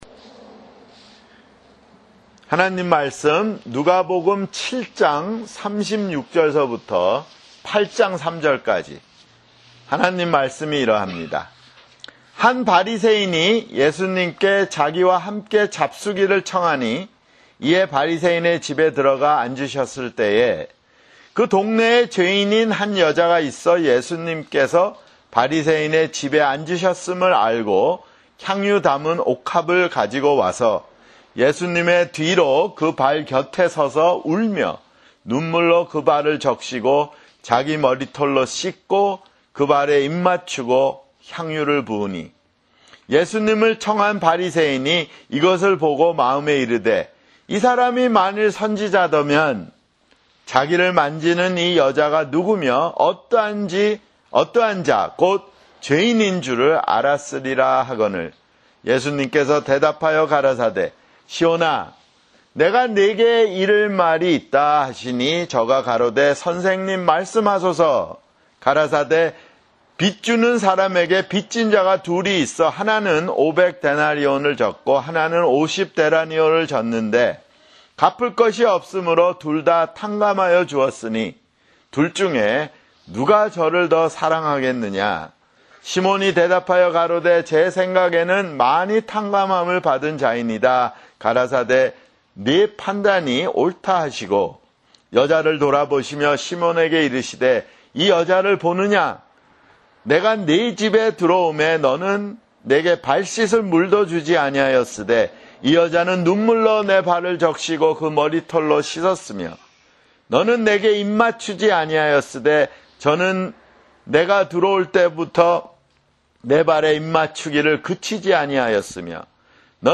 [주일설교] 누가복음 (54)